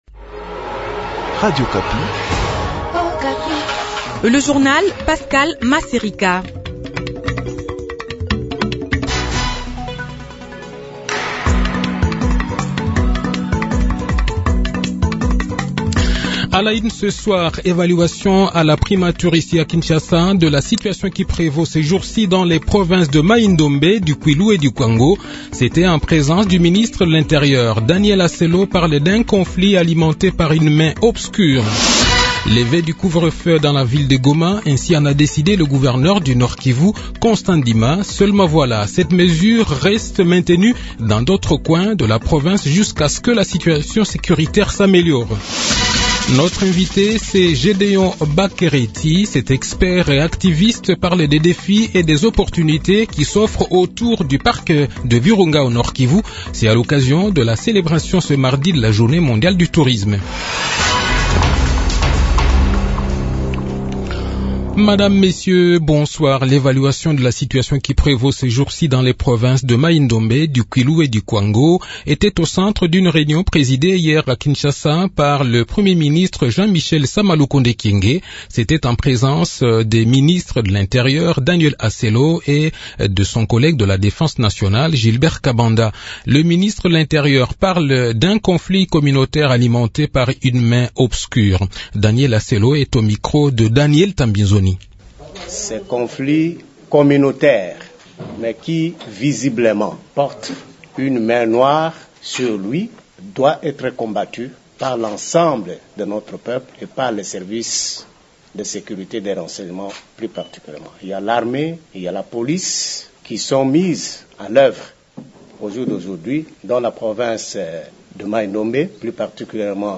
Le journal de 18 h, 27 Septembre 2022